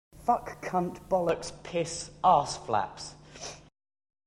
I just had to include it here so you can hear his speaking voice if you've never heard it before: it's so utterly different from what you'd expect (listen to a Cradle of Filth clip first!), that it's just really, really bizarre.
Dani speaks :)
danicurse.mp3